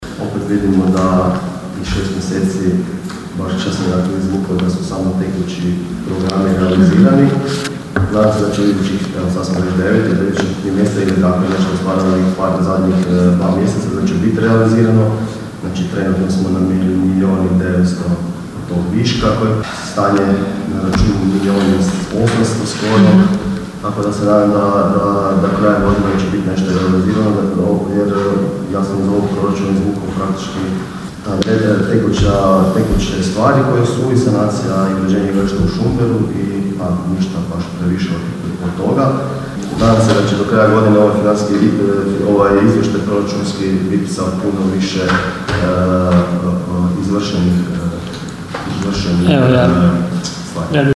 "Financijski stojimo jako dobro", primijetio je u raspravi vijećnik Demokrata Robi Čalović: (